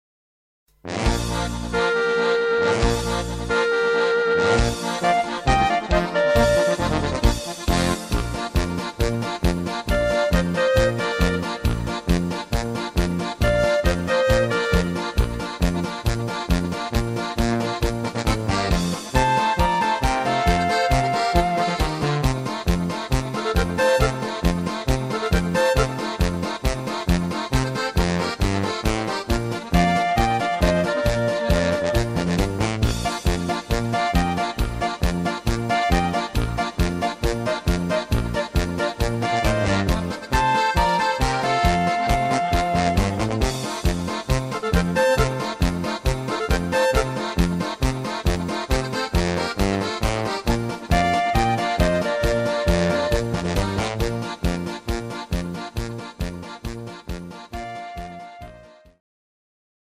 Oberkrainer Sound